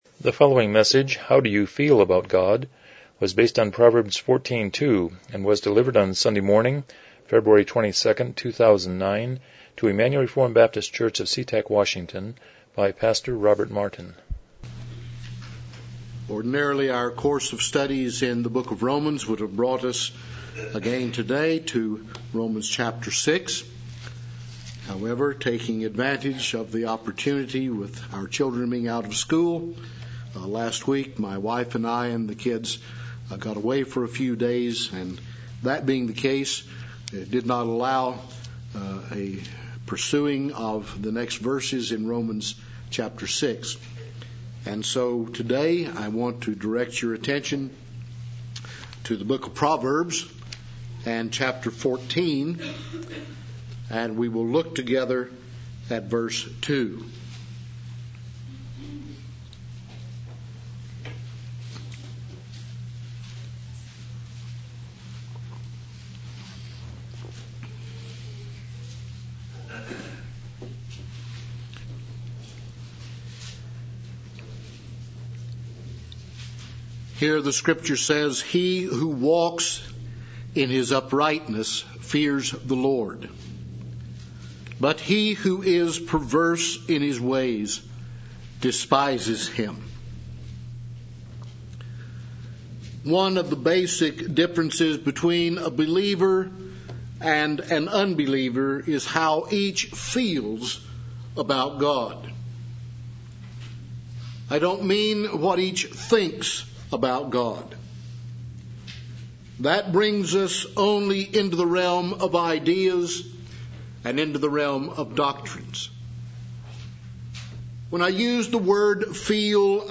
Proverbs 14:2 Service Type: Morning Worship « 22 God’s Response to Man’s Sin